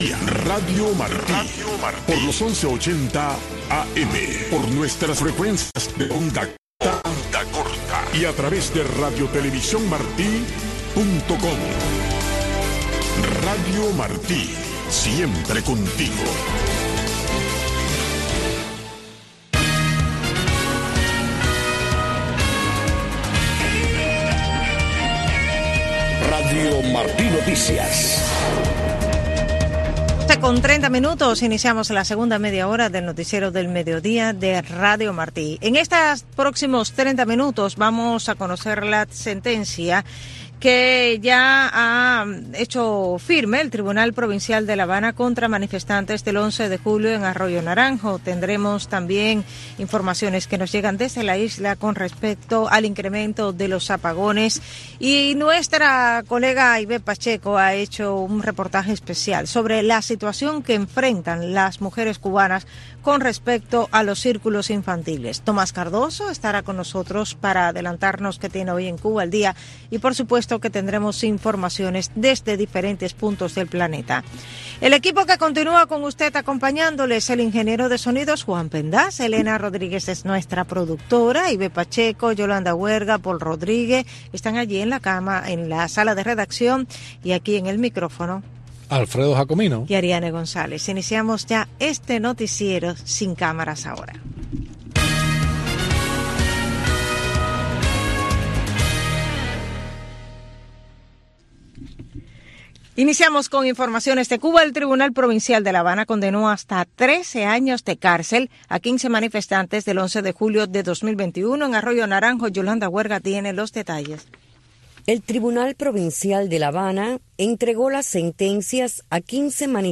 Noticiero Radio Martí presenta los hechos que hacen noticia en Cuba y el mundo.